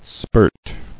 [spirt]